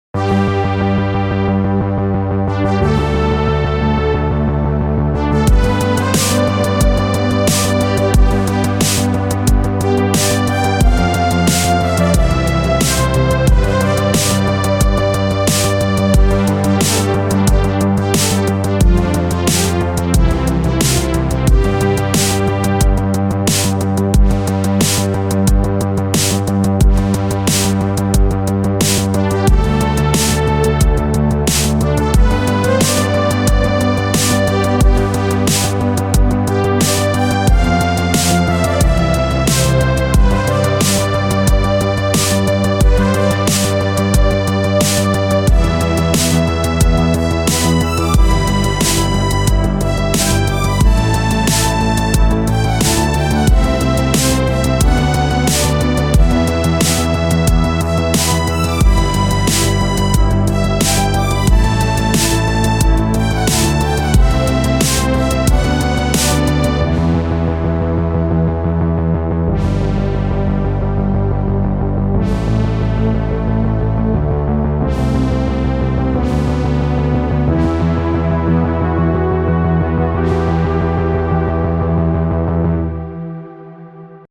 Retrowave